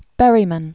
(bĕrē-mən), John Originally John Smith. 1914-1972.